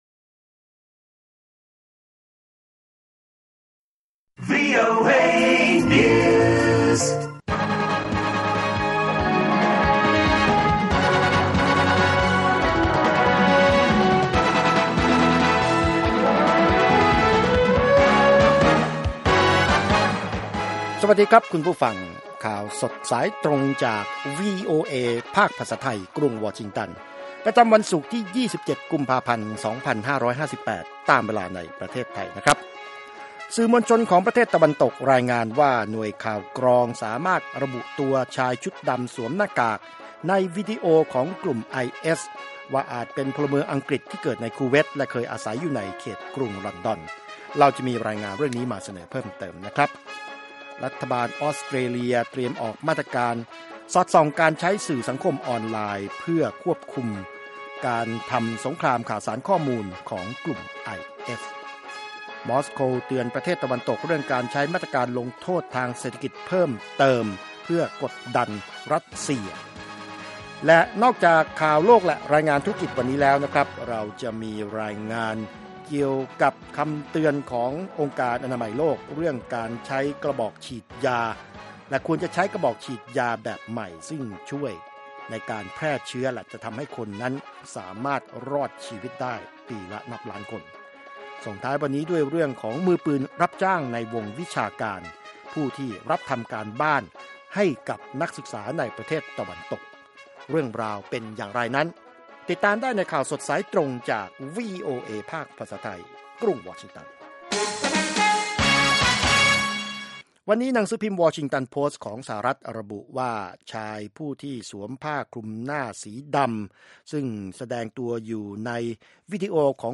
ข่าวสดสายตรงจากวีโอเอ ภาคภาษาไทย 8:30–9:00 น.